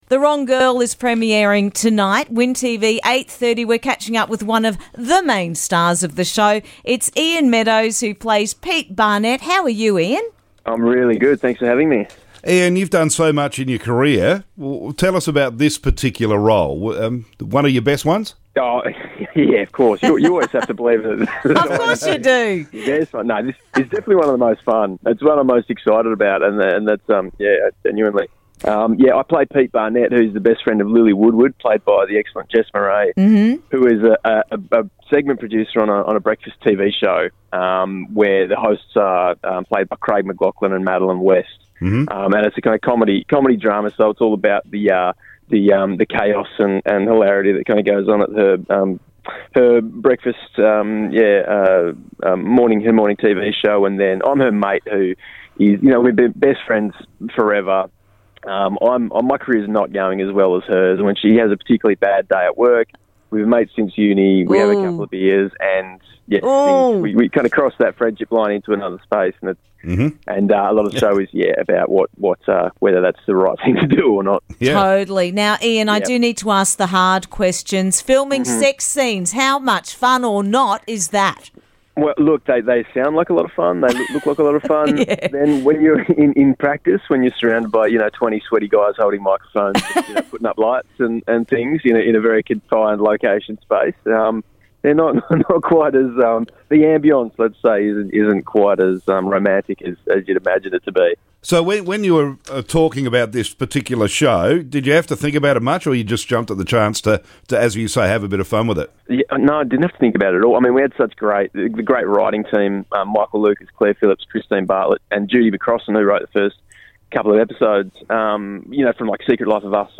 Interview - Ian Meadows from The Wrong Girl